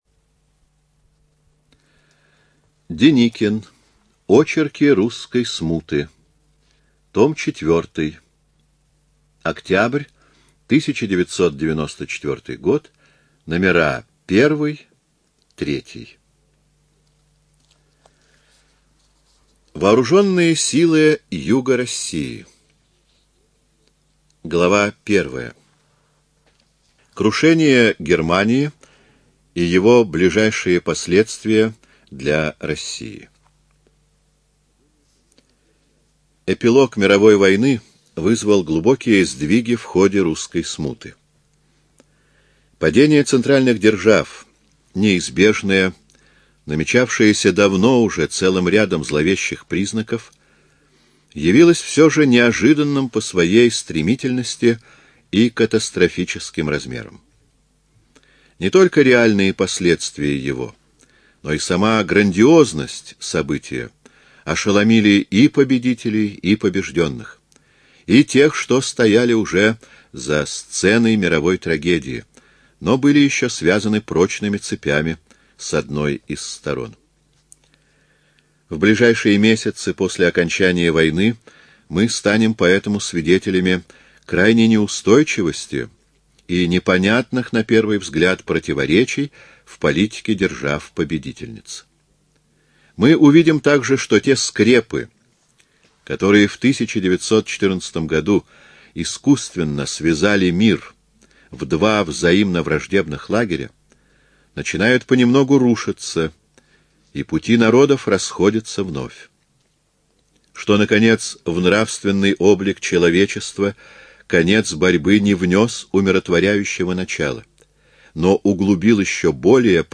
ЖанрИсторическая проза, Наука и образование
Студия звукозаписиЛогосвос